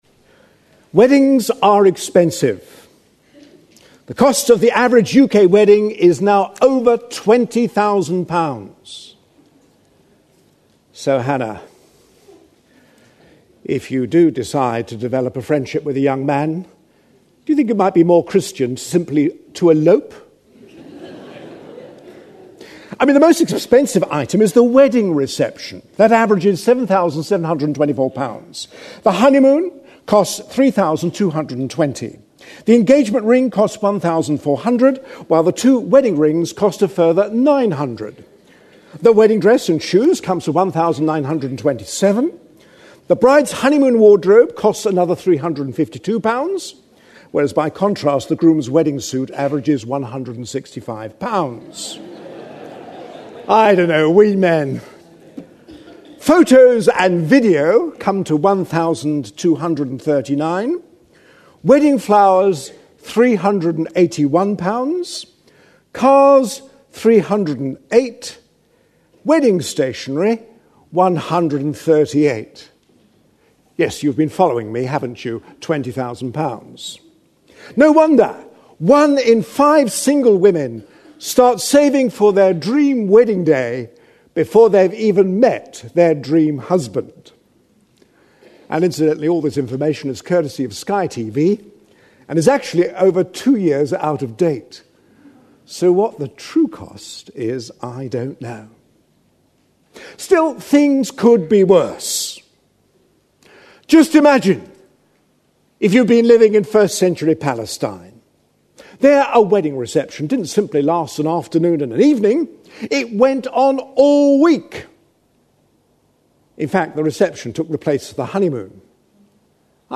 A sermon preached on 6th February, 2011, as part of our A Passion For.... series.